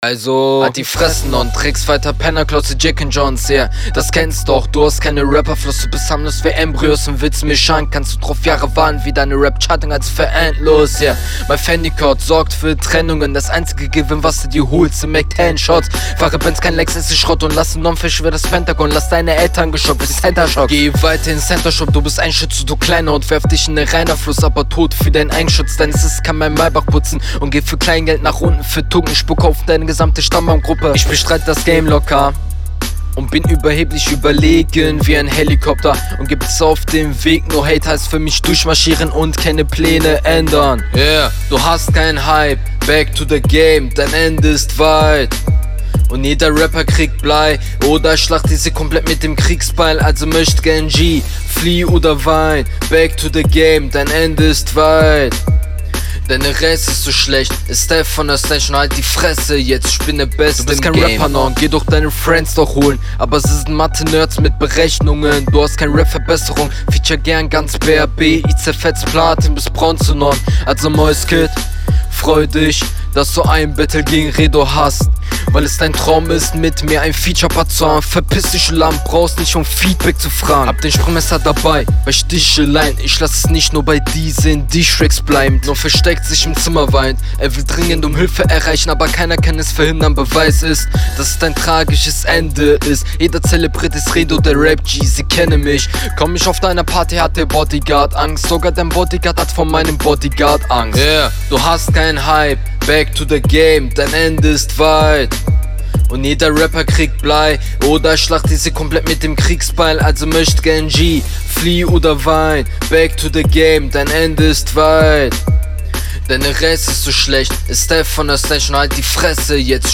Deine Vocals sind echt übersteuert digga..